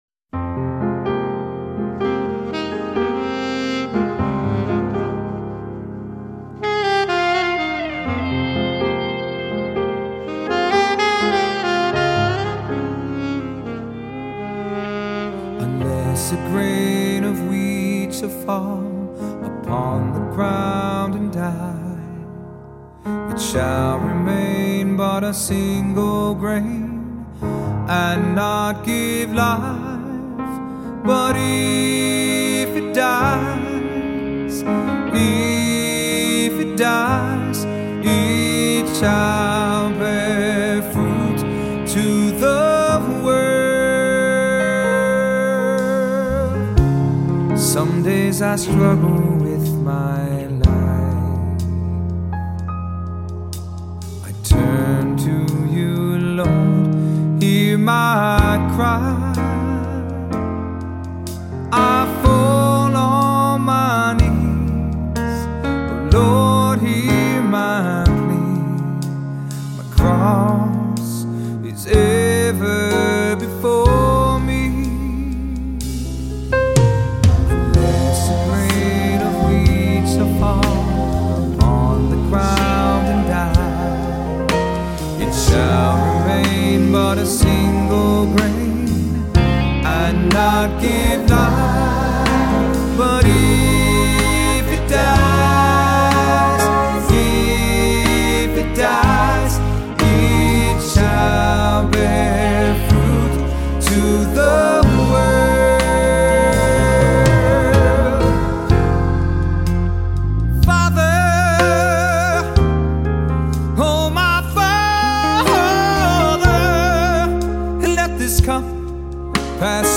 Voicing: SATB; Solo